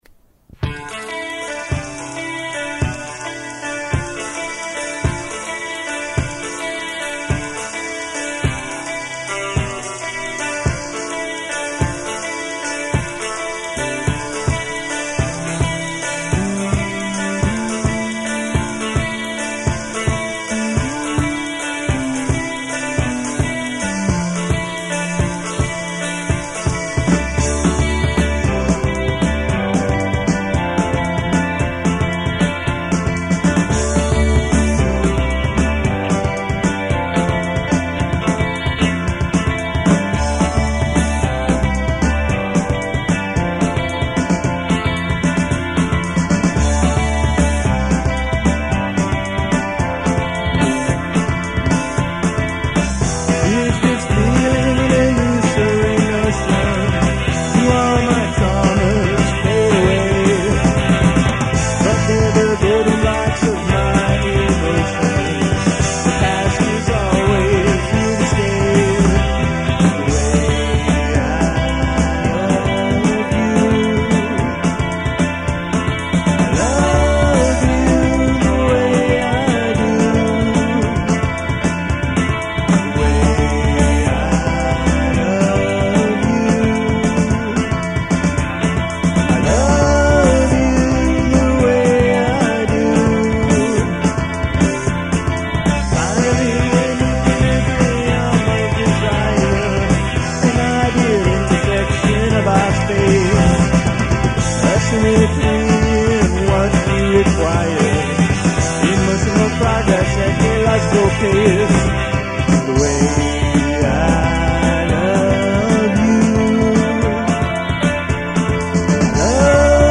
bass & vocals